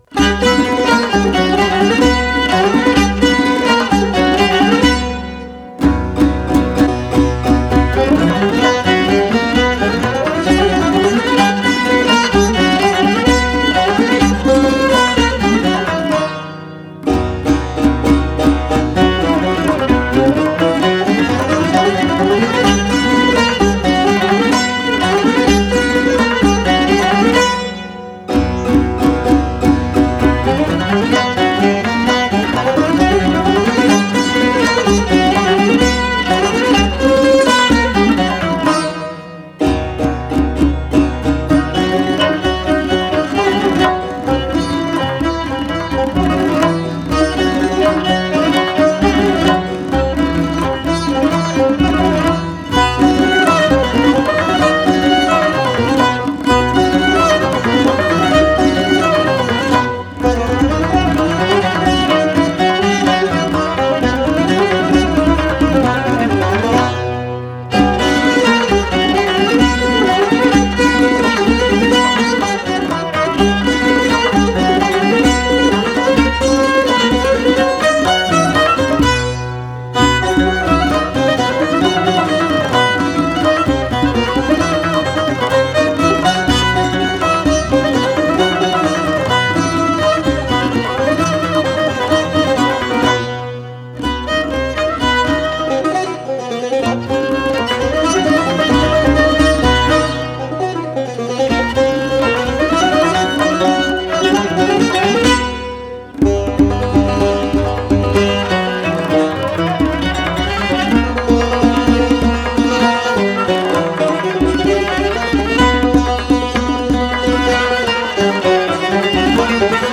Tasnif Chahargah